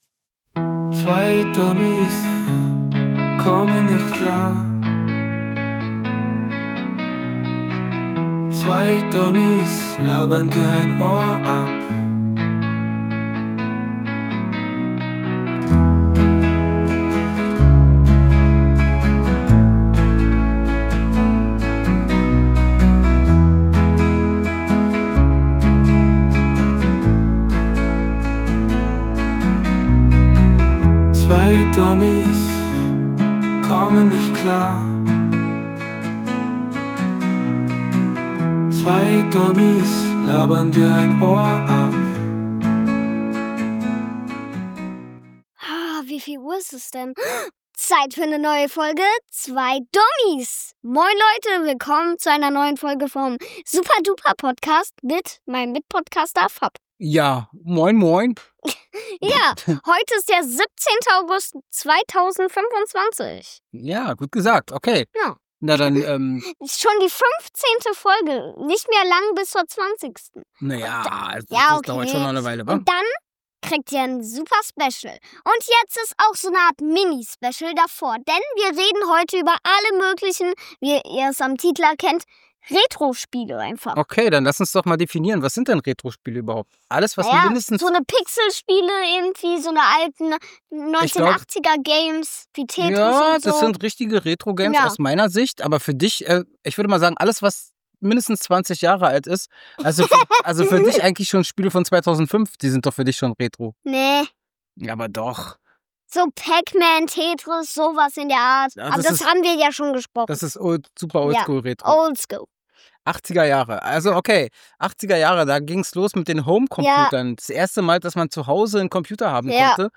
Der Podcast zeichnet sich durch einen informellen Stil aus, bei dem die Hosts auch persönliche Anekdoten und humorvolle Missverständnisse teilen, während sie die Geschichte und den anhaltenden Charme von Klassikern wie Pong und verschiedenen Flipper-Spielen erkunden.